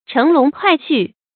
乘龍快婿 注音： ㄔㄥˊ ㄌㄨㄙˊ ㄎㄨㄞˋ ㄒㄩˋ 讀音讀法： 意思解釋： 乘龍：好比乘坐于龍上得道成仙；快婿：稱意的女婿。